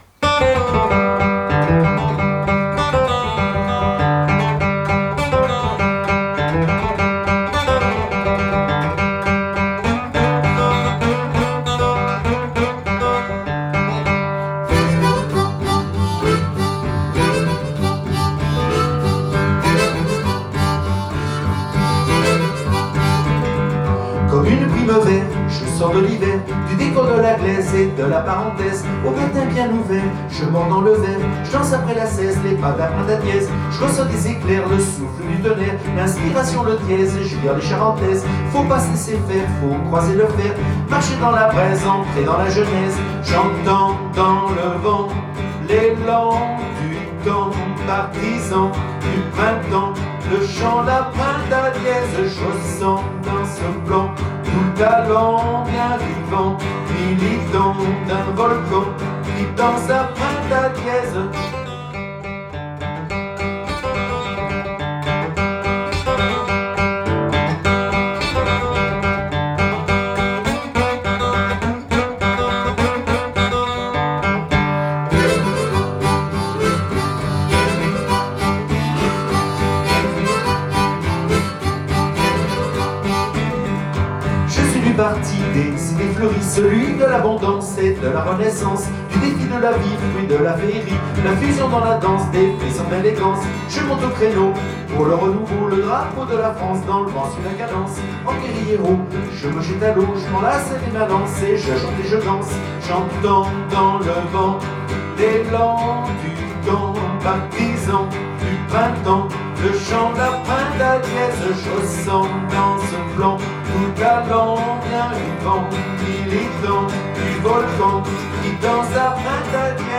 Hymne patriotique à la liberté commençant en country
et finissant en couple en quickstep.